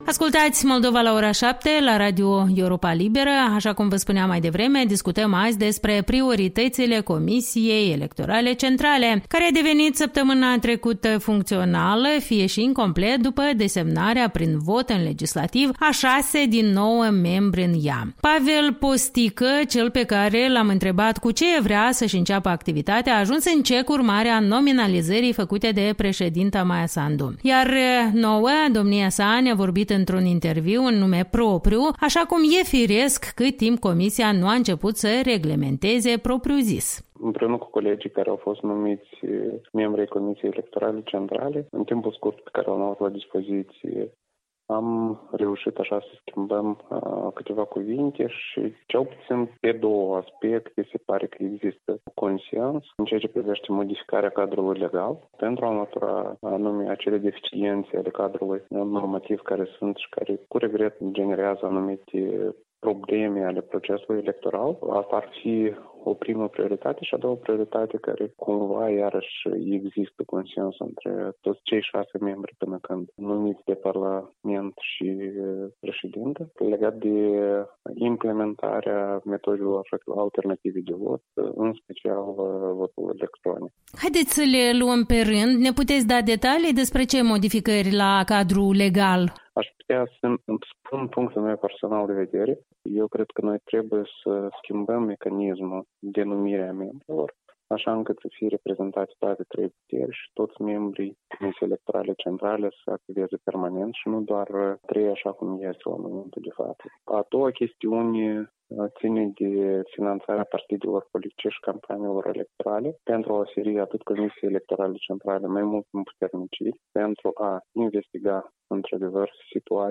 Interviu cu Pavel Postică, Promo_LEX si CEC